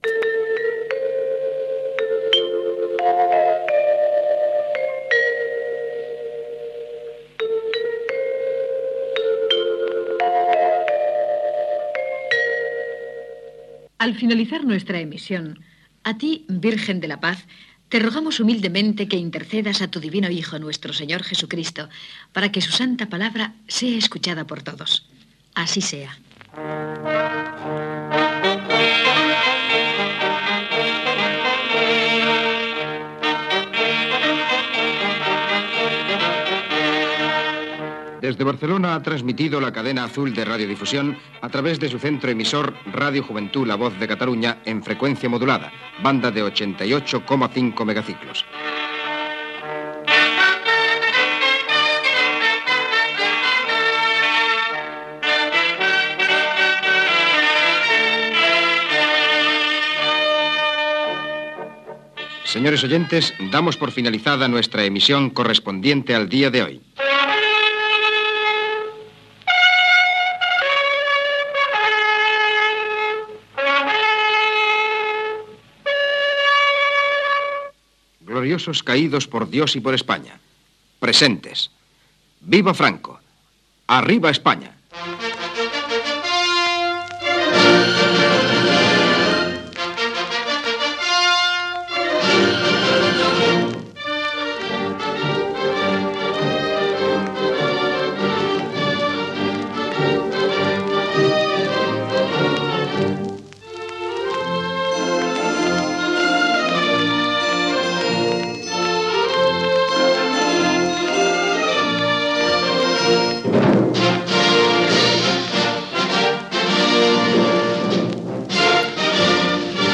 Tancament de l'emissió: sintonia de l'emissora (campanetes), oració, sardana, identificació i freqüència, toc de corneta,"viva Franco i arriba España", " Cara al sol" (himne de la Falange Española de las JONS) i himne espanyol